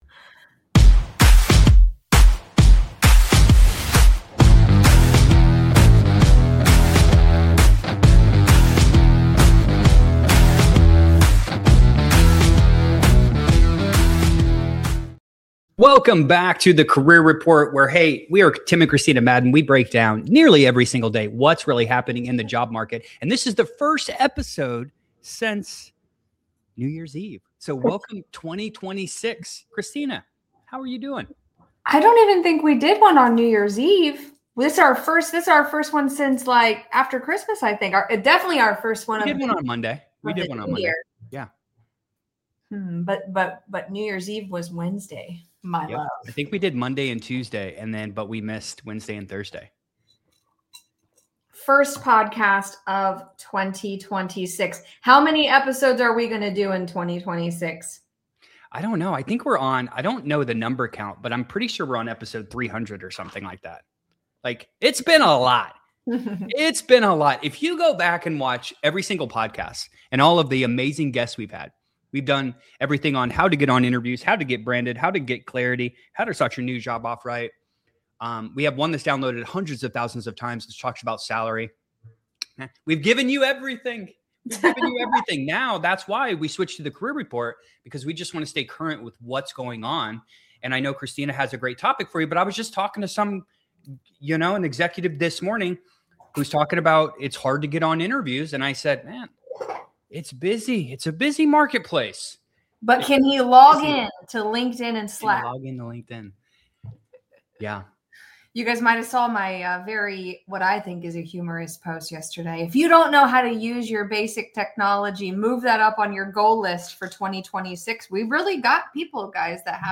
This conversation is about clarity over chaos.